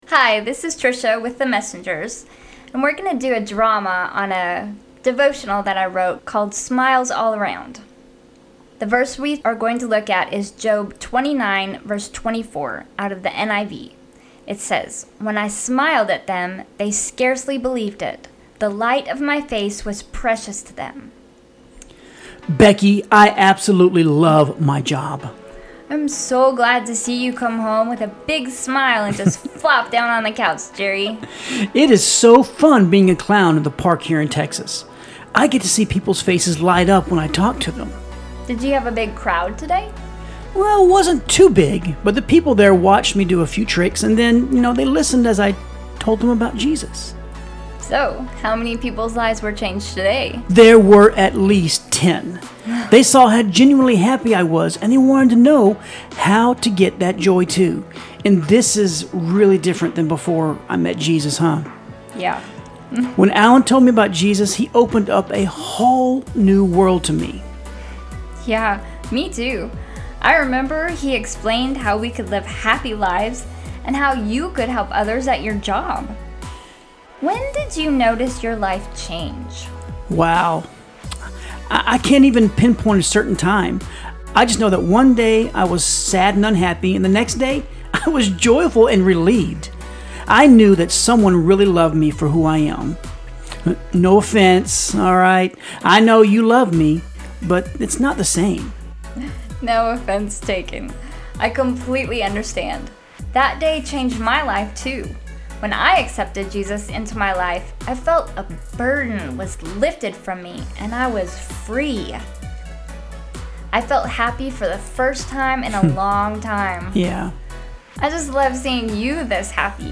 Smiles All Around – Audio Devotional / Drama